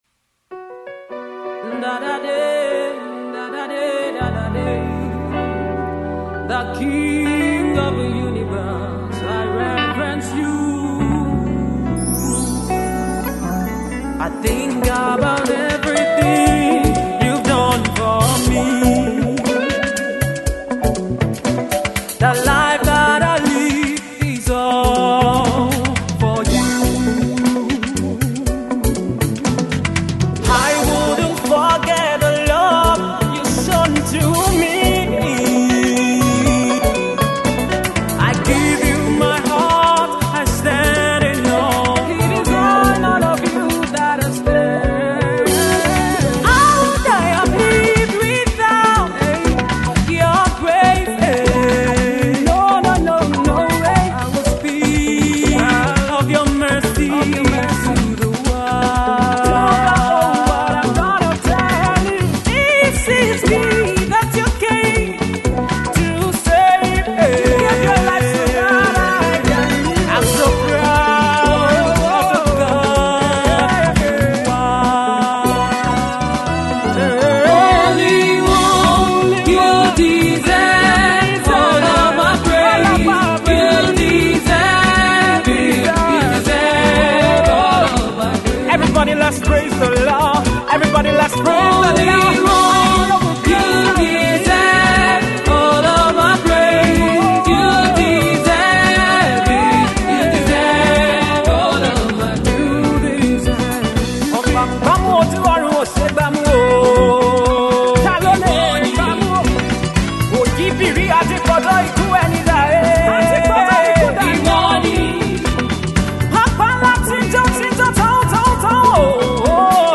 an African Praise song blended with African Instrumentation